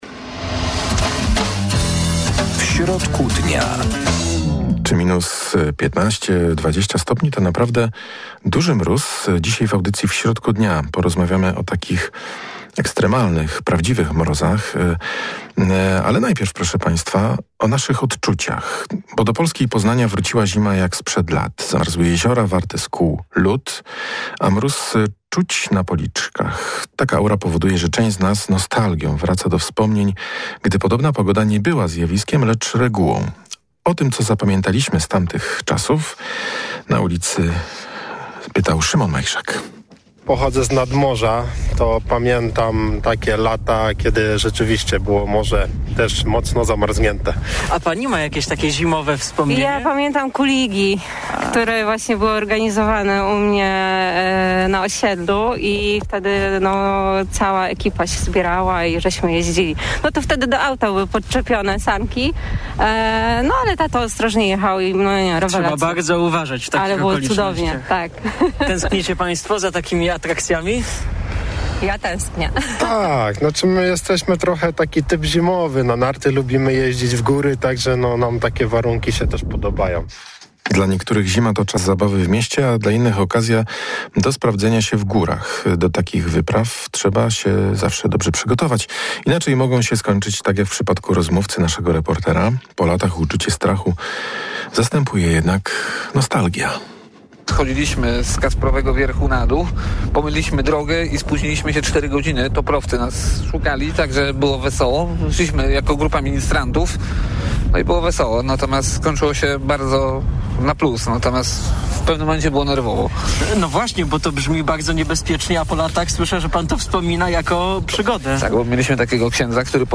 Czy minus dwadzieścia stopni, to naprawdę duży mróz? Dzisiaj w audycji W środku dnia porozmawiamy o prawdziwych mrozach. W naszym studiu będzie jeden z Łowców Mrozów